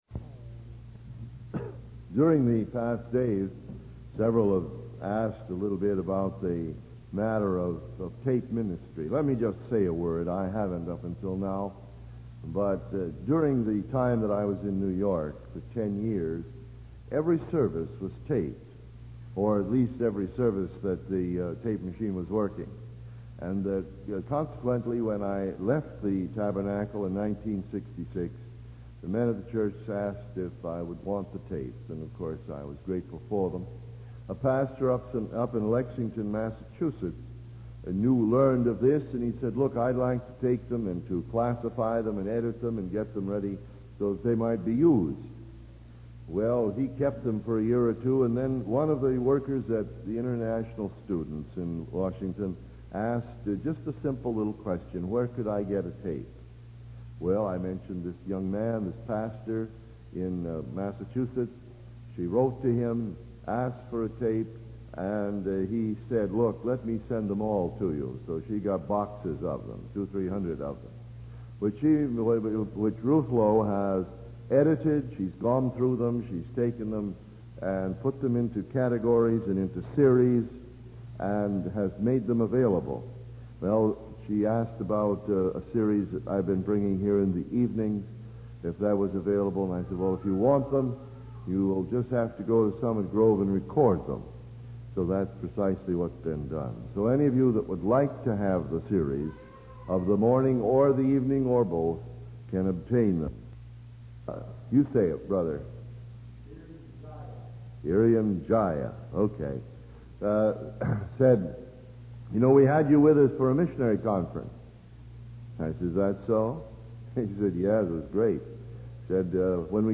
The speaker also discusses the use of cassette tapes as a new way to disseminate God's truth and suggests that busy people can listen to sermons while driving. The sermon concludes with a humorous anecdote about a missionary conference and the benefits of using tapes for ministry.